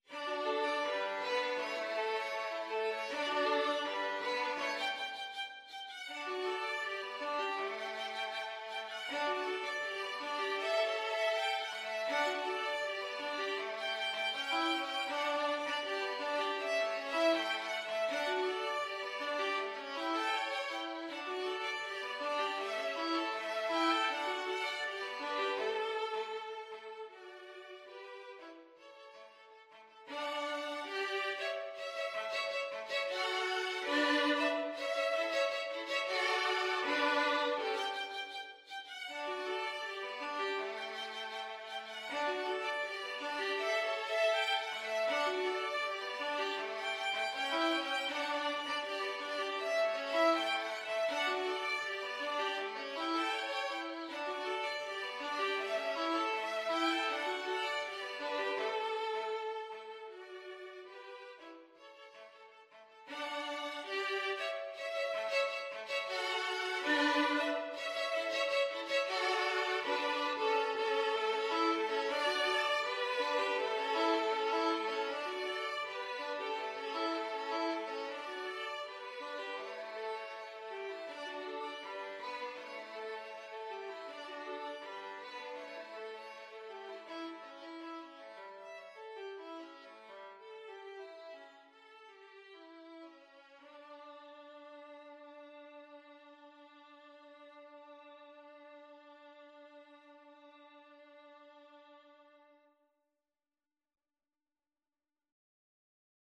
Free Sheet music for Violin Duet
4/4 (View more 4/4 Music)
D major (Sounding Pitch) (View more D major Music for Violin Duet )
Fast and bright = c. 160
Mexican
la_bamba_2VLN.mp3